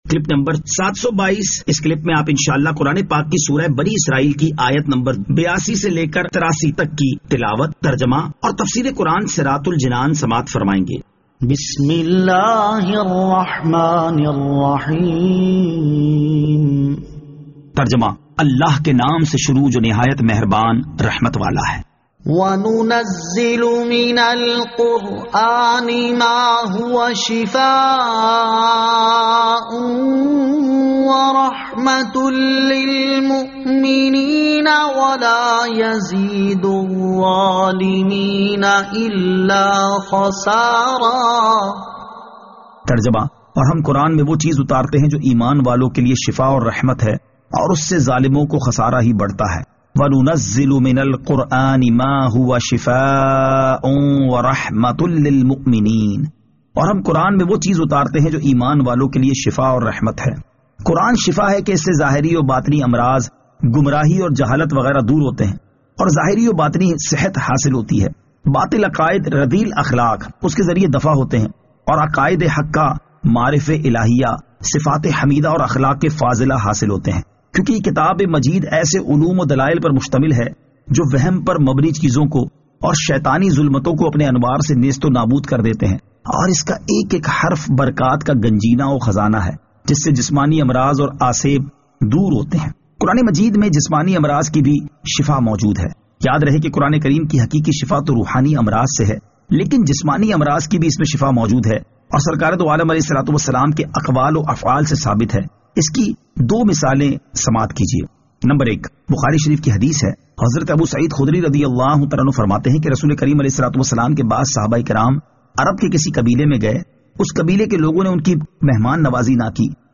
Surah Al-Isra Ayat 82 To 83 Tilawat , Tarjama , Tafseer